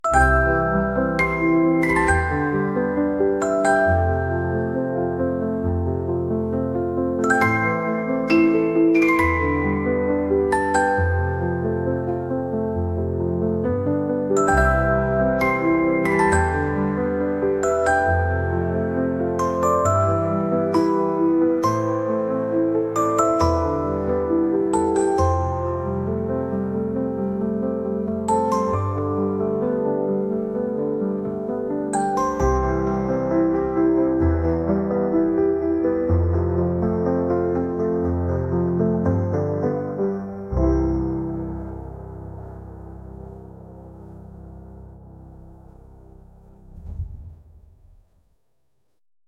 「幻想的」